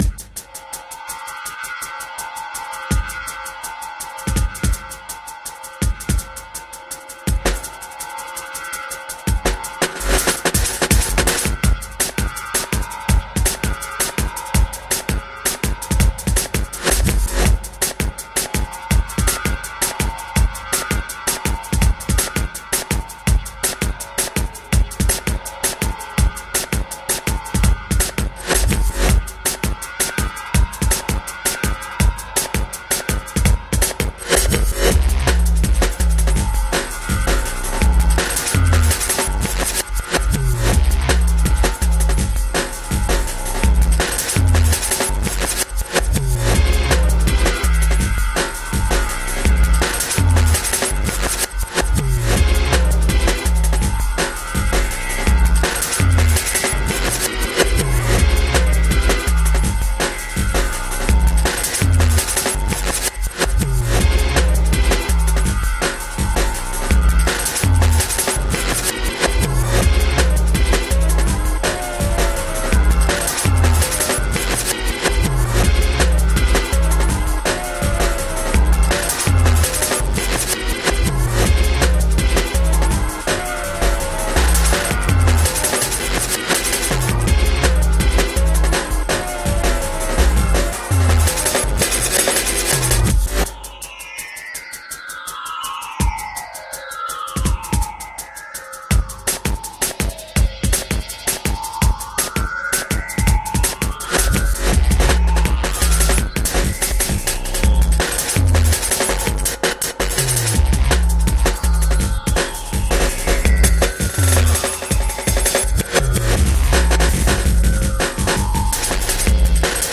Genre: Electronic.